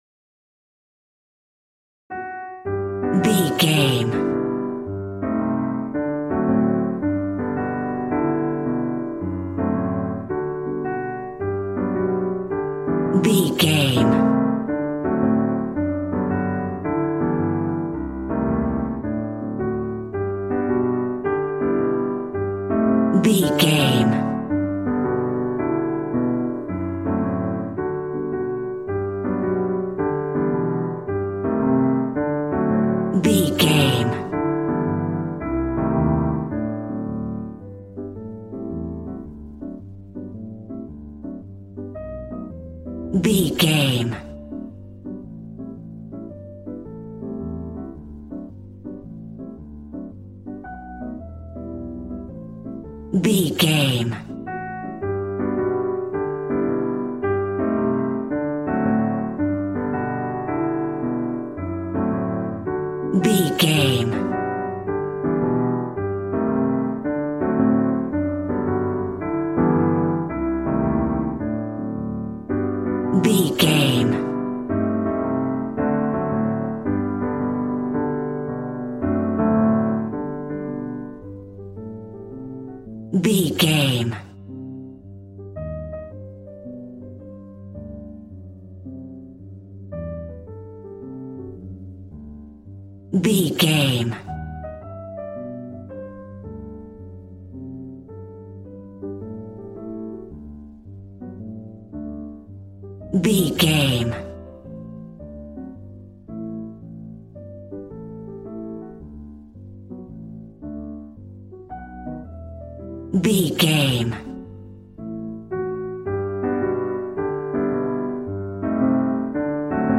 Smooth jazz piano mixed with jazz bass and cool jazz drums.,
Aeolian/Minor
piano